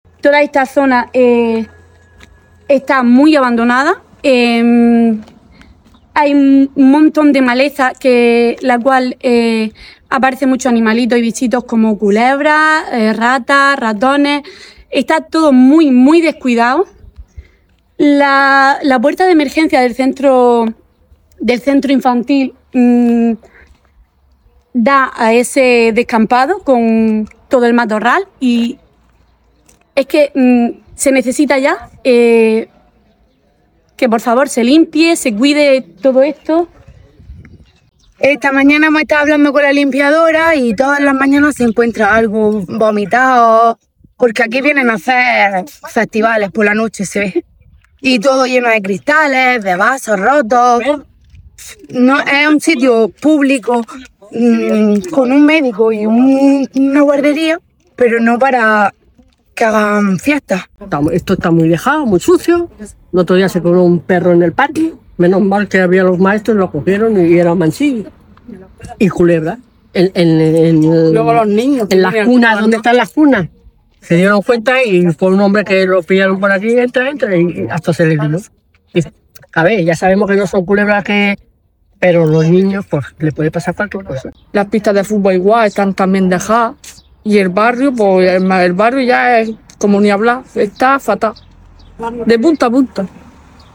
madres-guarderia.mp3